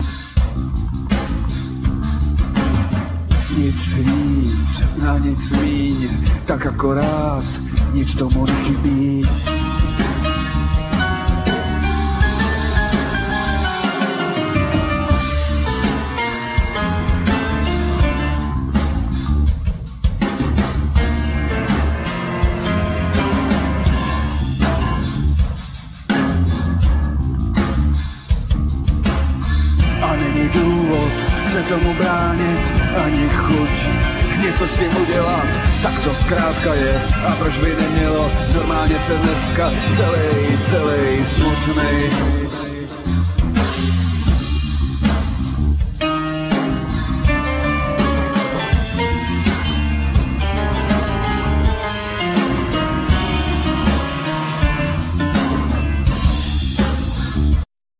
Vocal,Piano
Drums,Nastroje,Djembe
Bass
Saxophone